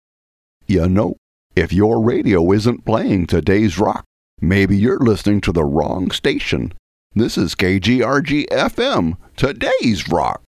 Station Bumper-Mr. Rogers on Acid